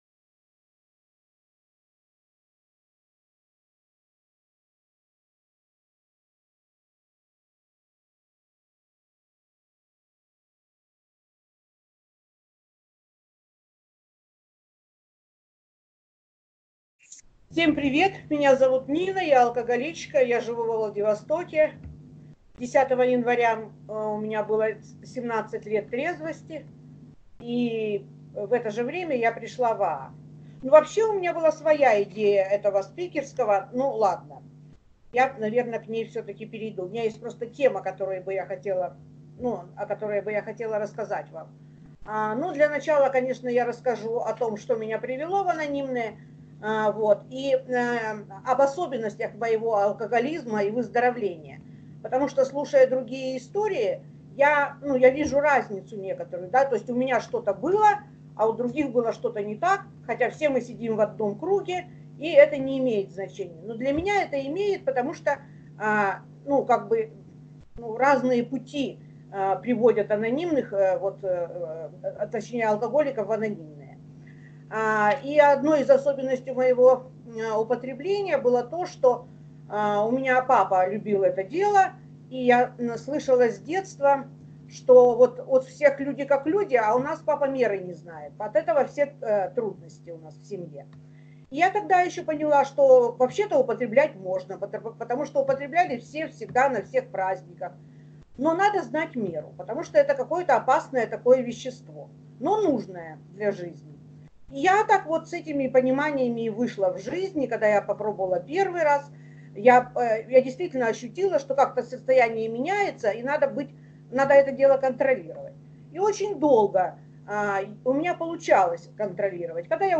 Проведен очередной субботний семинар онлайн в клубе.